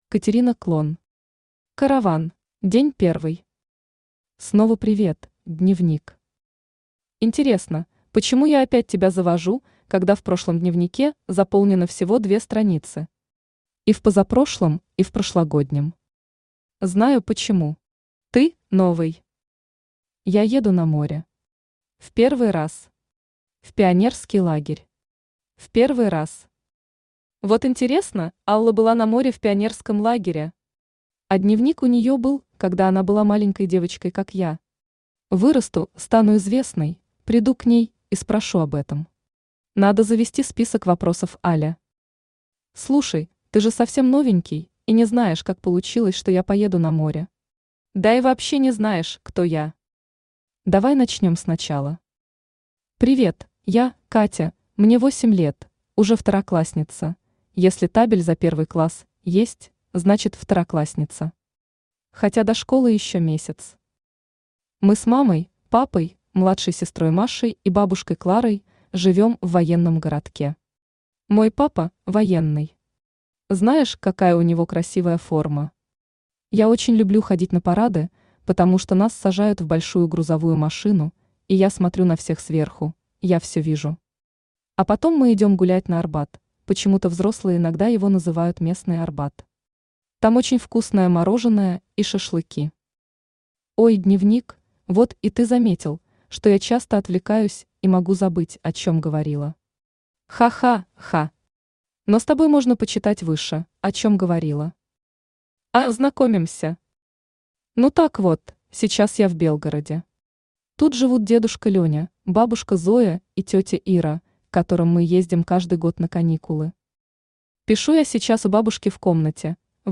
Аудиокнига Караван | Библиотека аудиокниг
Aудиокнига Караван Автор Катерина Клон Читает аудиокнигу Авточтец ЛитРес.